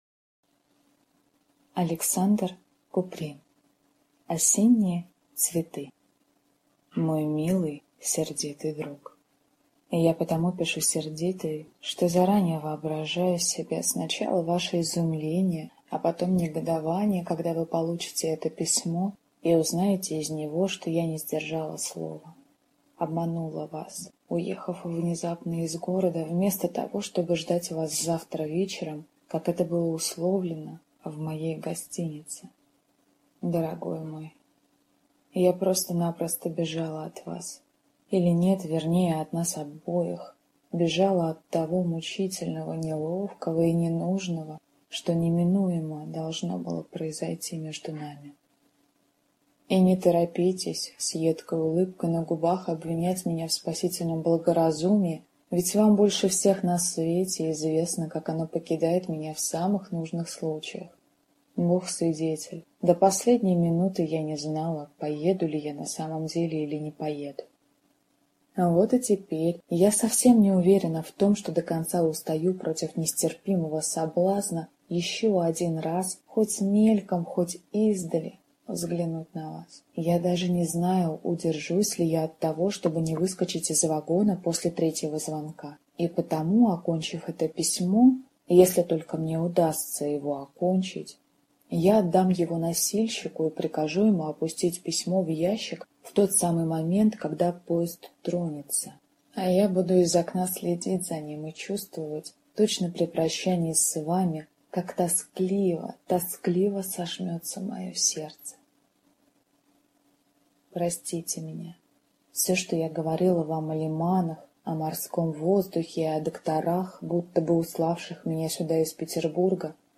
Аудиокнига Осенние цветы | Библиотека аудиокниг